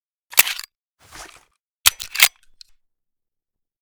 tt33_reload.ogg